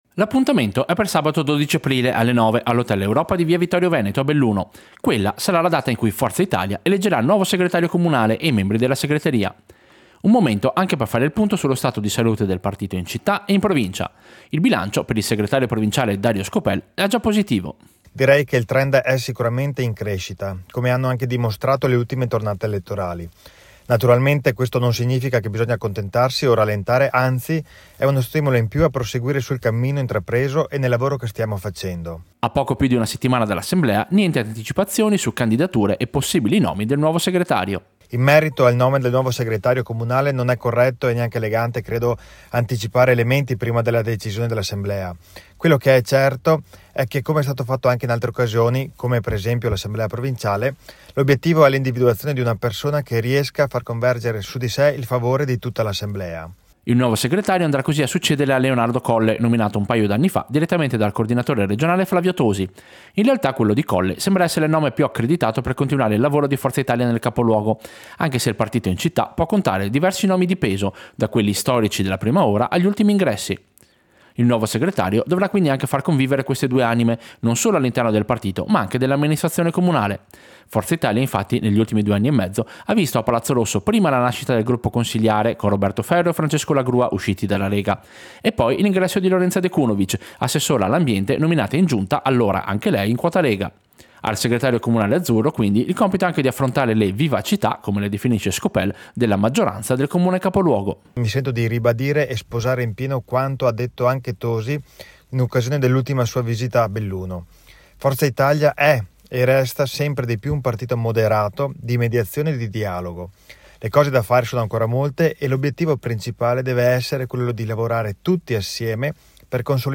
Servizio-Assemblea-comunale-Forza-Italia.mp3